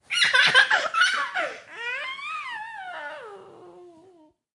出世 " 婴儿出生 第一时刻 吮吸
描述：用DS40记录新生儿的第一次吸吮。
标签： 婴儿 出生
声道立体声